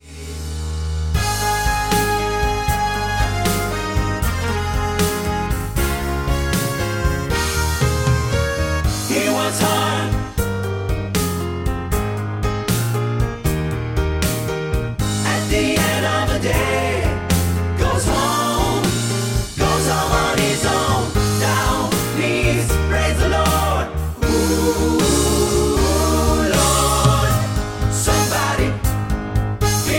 Backing track files: Musical/Film/TV (484)
Buy With Backing Vocals.